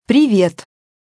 Привет женщина робот поприветствовала